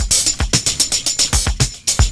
112CYMB10.wav